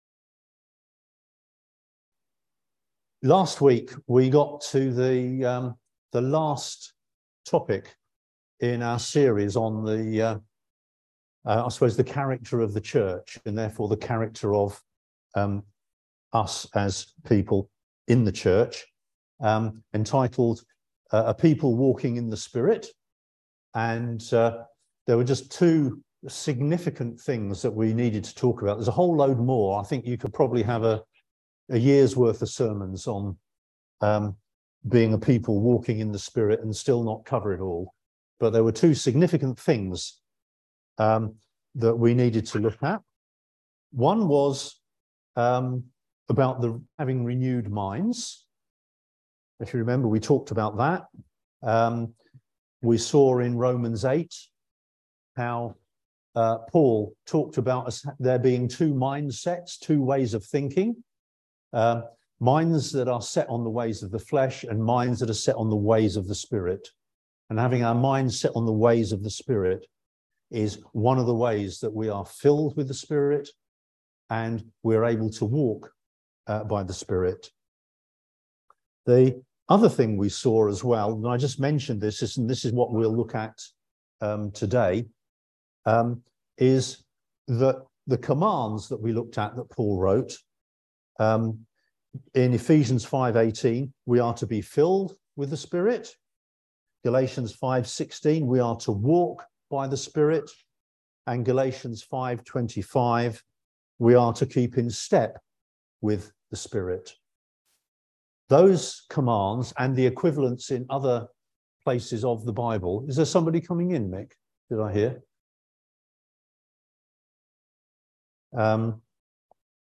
Service Type: Sunday Service Topics: Godliness , Holy Spirit , Renewed Mind , Sanctification , Sin